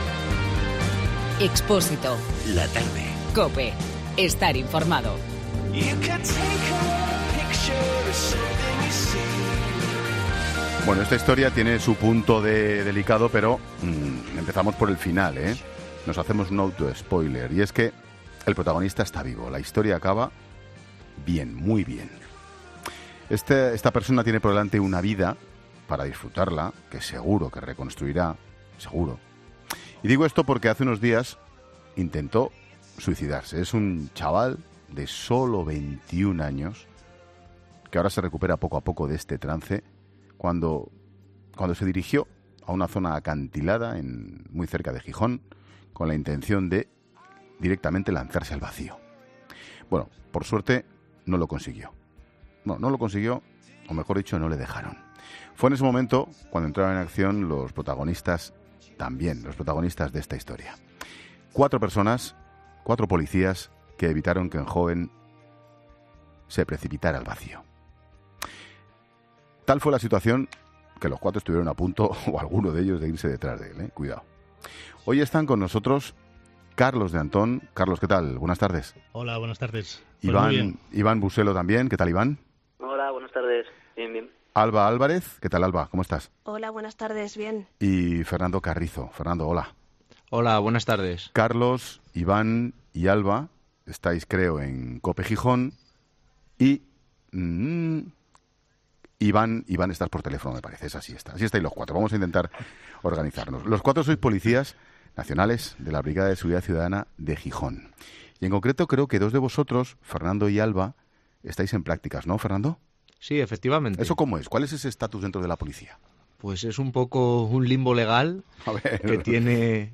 Ángel Expósito entrevista a los cuatro héroes del rescate que pudo acabar en tragedia